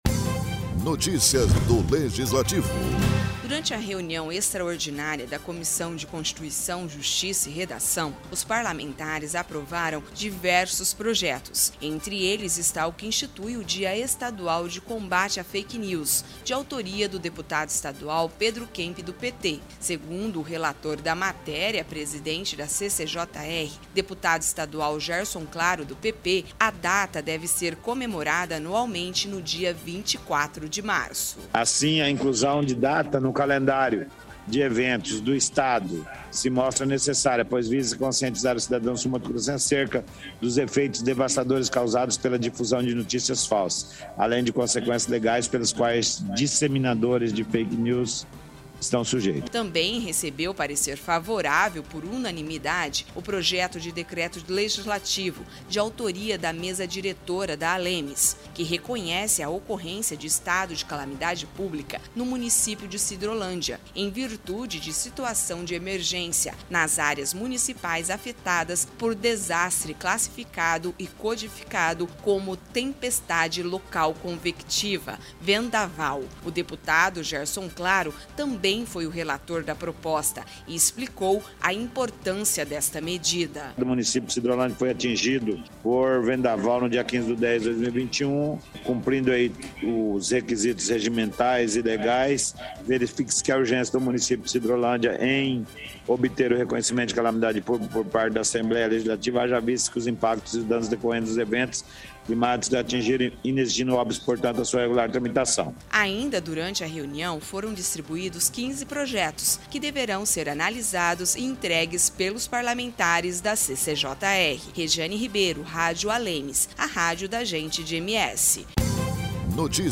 Durante a reunião extraordinária da Comissão de Constituição, Justiça e Redação (CCJR), os parlamentares aprovaram diversos projetos, entre eles está o que institui o Dia Estadual do Combate à Fake News de autoria de Pedro Kemp (PT). A data deverá ser celebrada anualmente, no dia 24 de março.